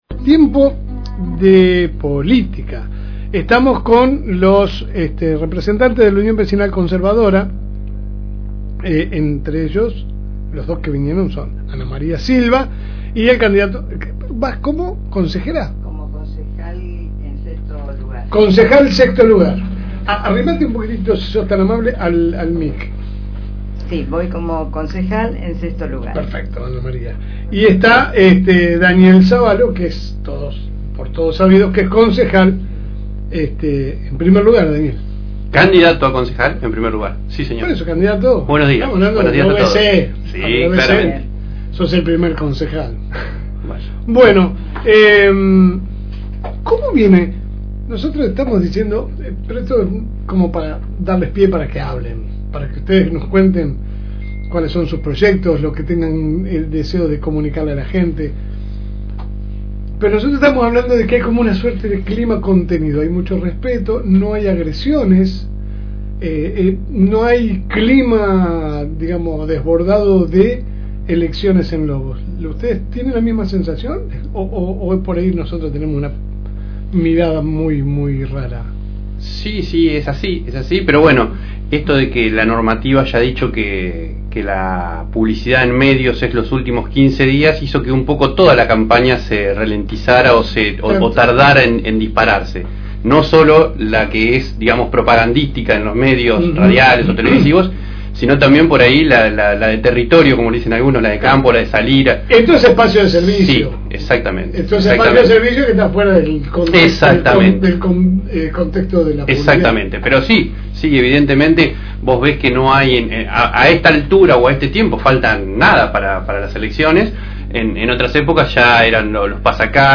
En esta ocasión pasaron por los estudios de la FM Reencuentro